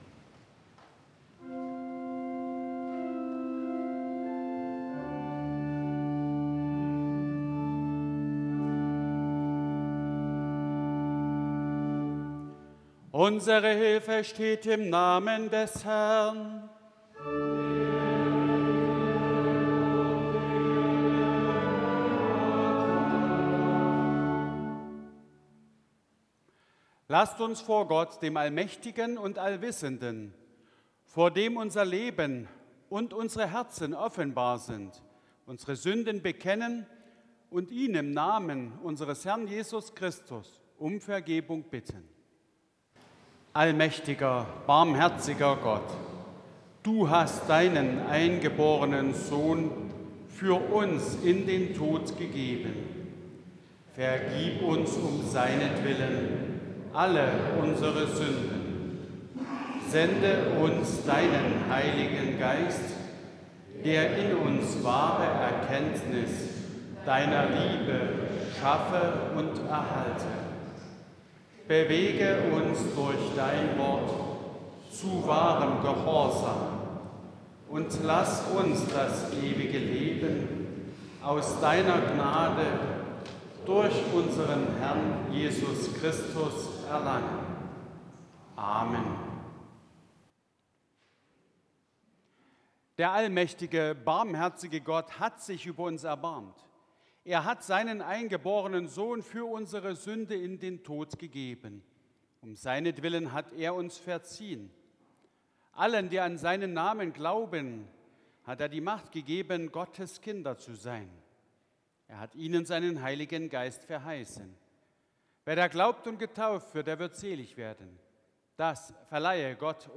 Audiomitschnitt unseres Gottesdienstes vom Vorletzten Sonntag im Kirchenjahr 2024